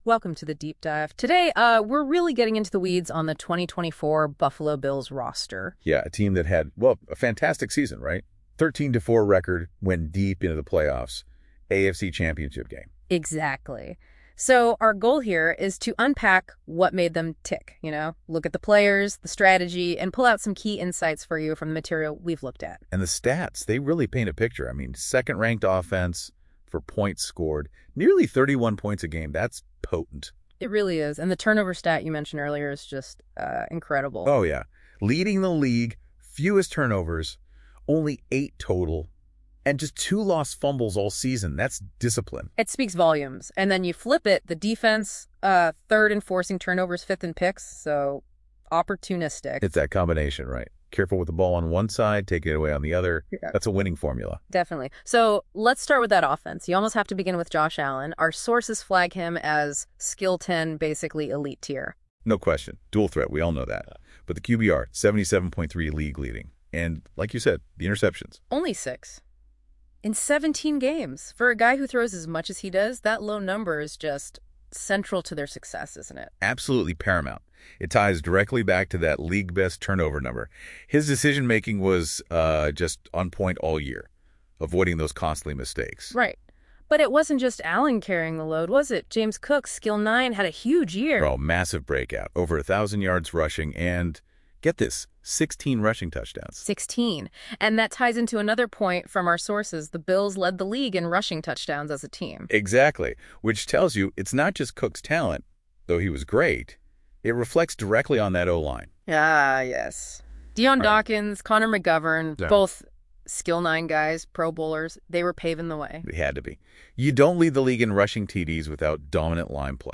Click each link to view below 2024 Season Interactive Analysis Infographic Full Report (PDF) Audio analysis between two AI commentators Season Quiz - Opens a new TAB Or: Download all (Zip File)